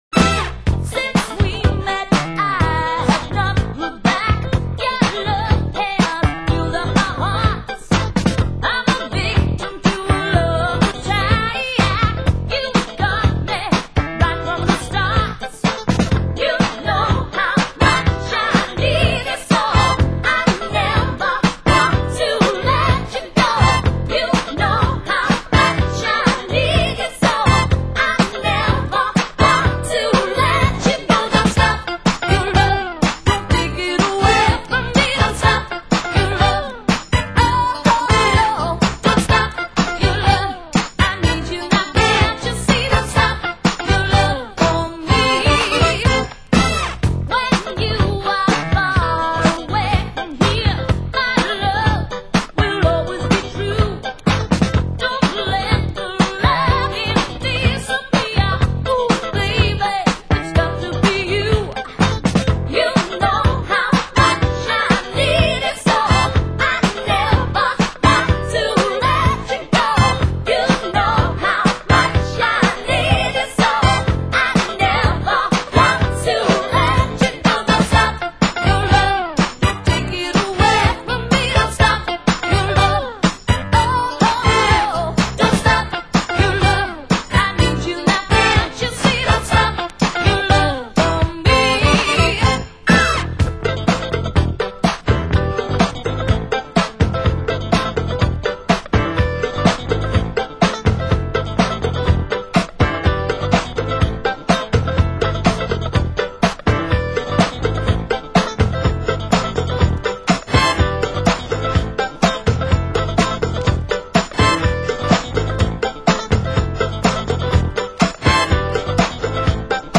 Genre Disco